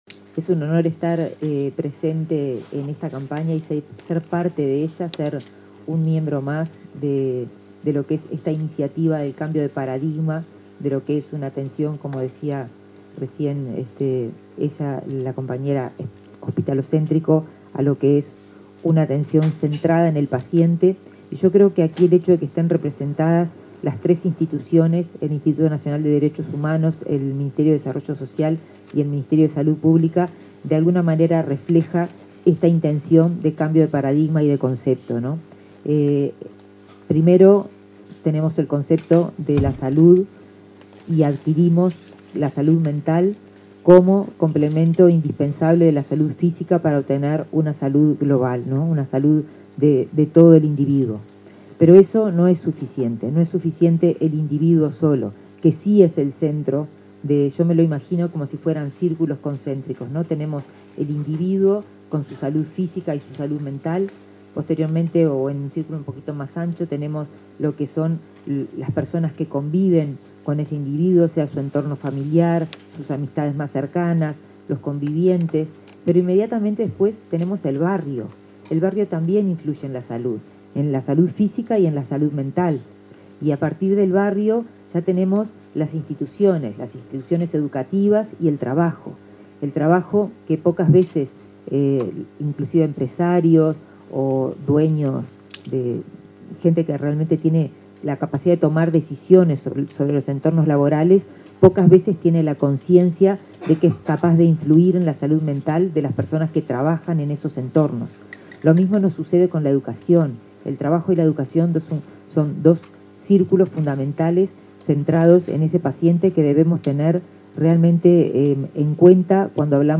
Palabra de autoridades en lanzamiento de Campaña Intersectorial 2023 de Salud Mental
Este lunes 9 en Montevideo, la ministra de Salud Pública, Karina Rando, y su par de Desarrollo Social, Martín Lema, participaron del lanzamiento de la